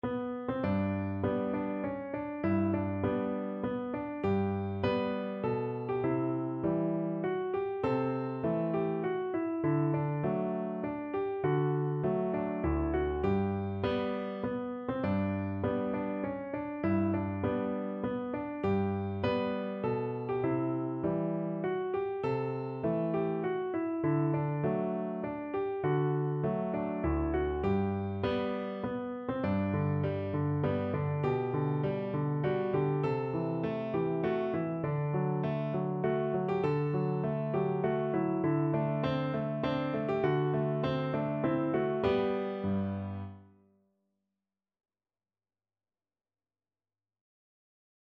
Free Sheet music for Piano
No parts available for this pieces as it is for solo piano.
G major (Sounding Pitch) (View more G major Music for Piano )
Moderato
3/4 (View more 3/4 Music)
Classical (View more Classical Piano Music)
rolling_home_PNO.mp3